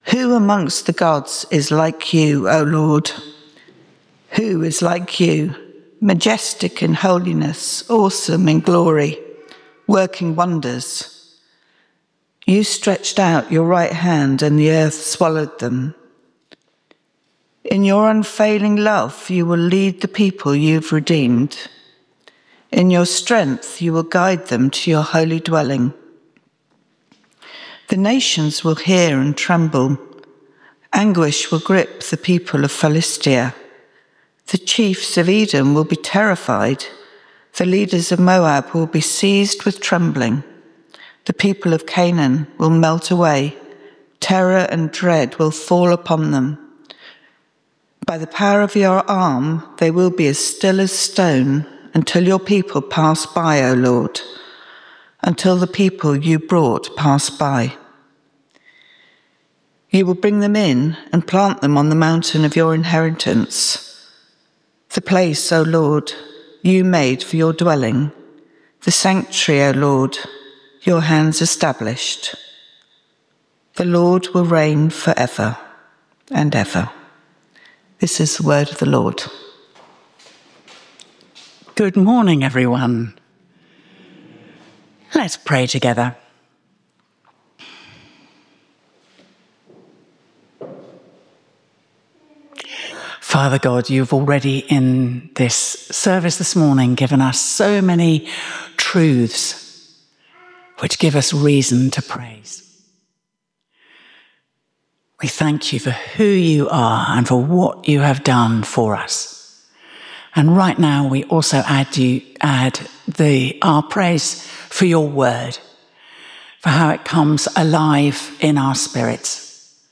St Mary’s, Slaugham – Informal Worship Speaker